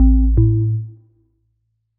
Melodic Power On 10.wav